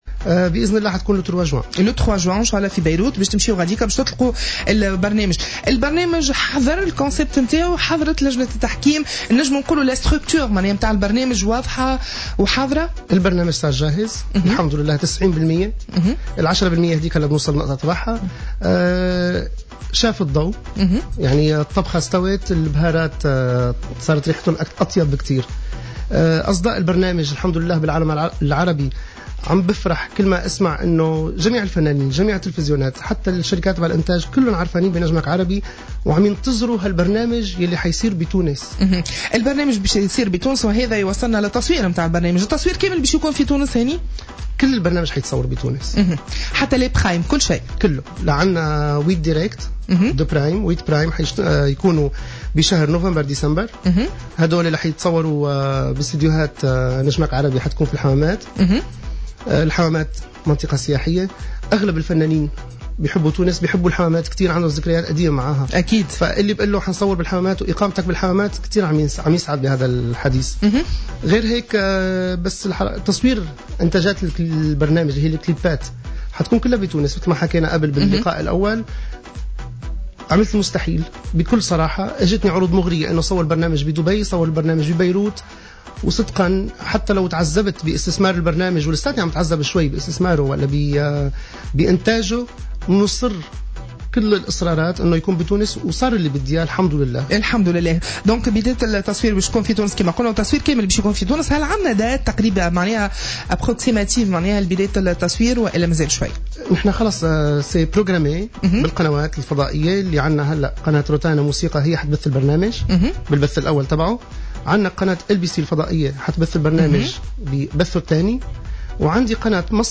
مداخلة له اليوم على "الجوهرة أف أم"